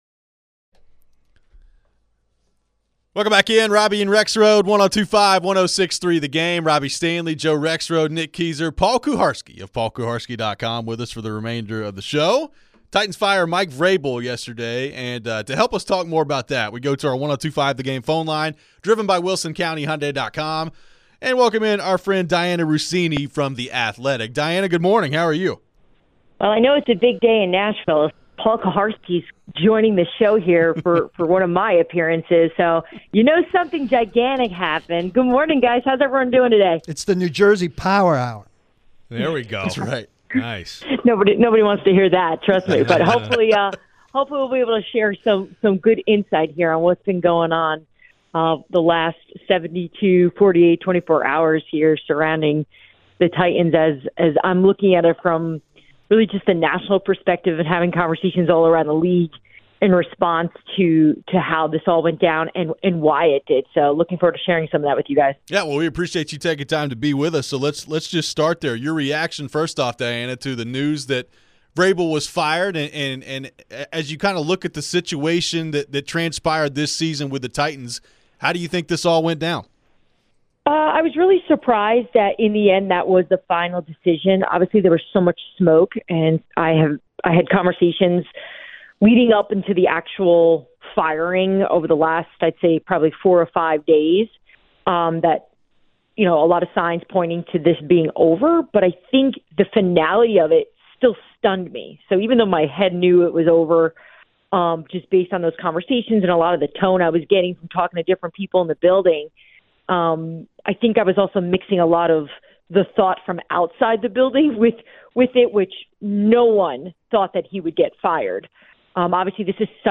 Diana Russini Interview (1-10-24)